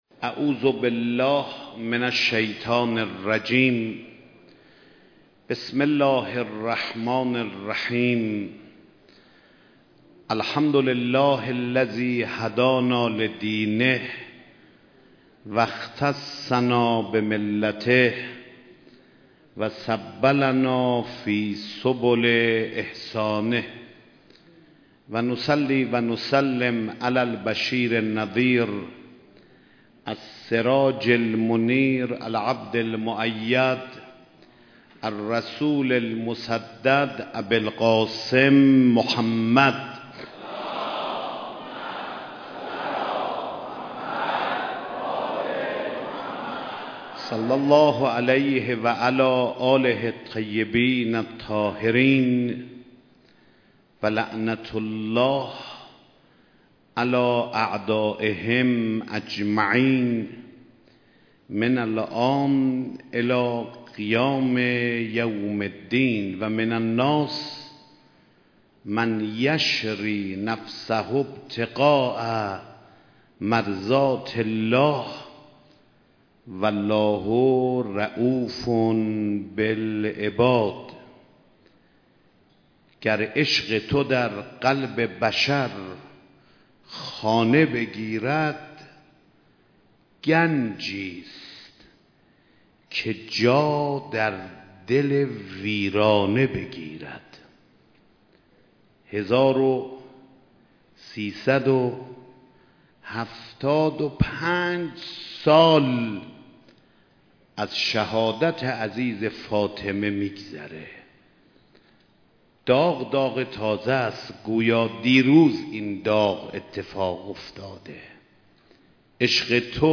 مراسم شب عاشورای حسینی
مراسم عزاداری شب عاشورا با حضور رهبر معظم انقلاب اسلامی برگزار شد
سخنرانی حجت الاسلام والمسلمین سید احمد خاتمی